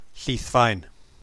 To hear how to pronounce Llithfaen, press play: